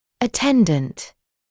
[ə’tendənt][ə’tendənt]сопровождающий, сопутствующий; помощник